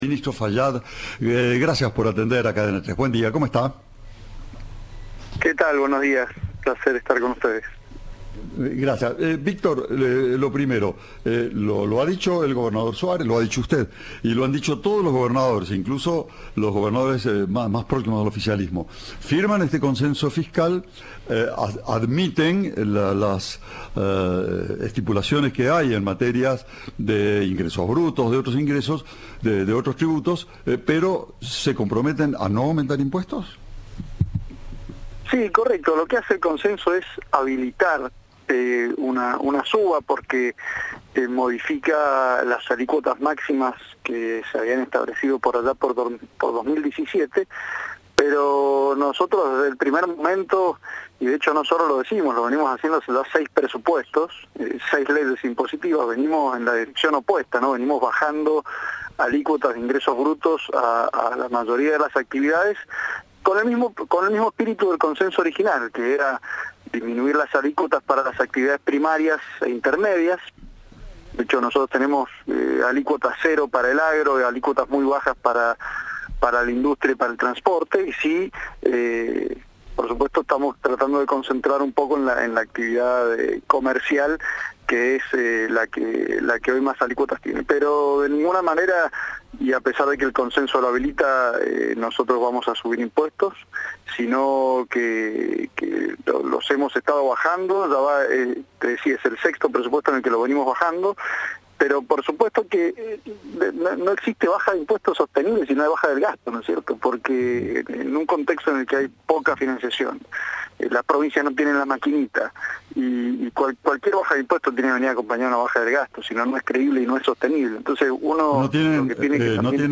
Victor Fayad, ministro de Hacienda de la provincia, dijo a Cadena 3 que por el contrario trabajan para bajarlos, pero para eso aclaró que tiene que haber una reducción de gastos.